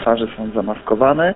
Mówi wiceprezydent Ełku Artur Urbański.
Artur-Urbański-1.mp3